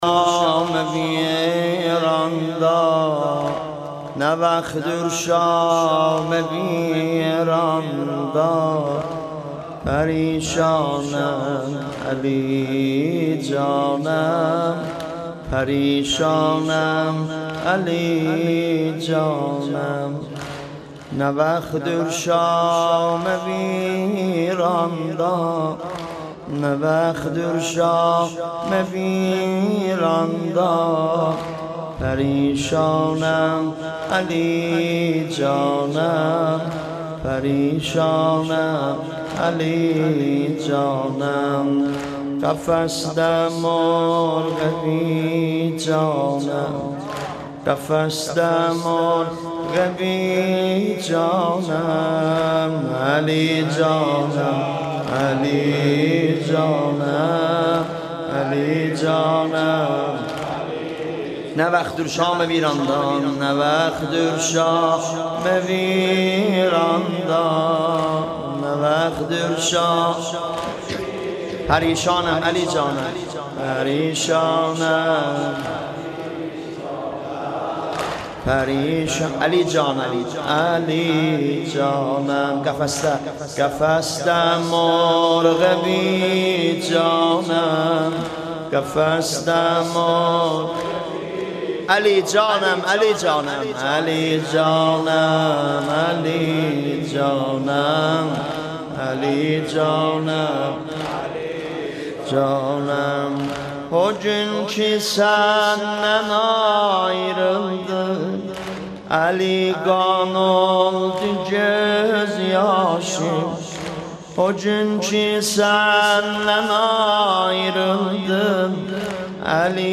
شب سوم محرم مداحی آذری نوحه ترکی